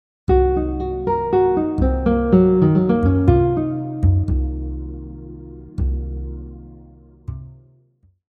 Example 4 also includes arpeggio note skipping and scale notes from the C Locrian scale).
half-diminished-arpeggio-example-4.mp3